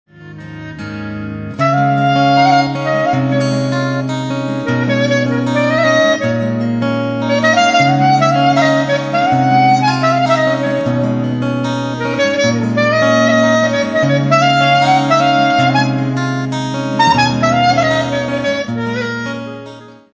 chitarra acustica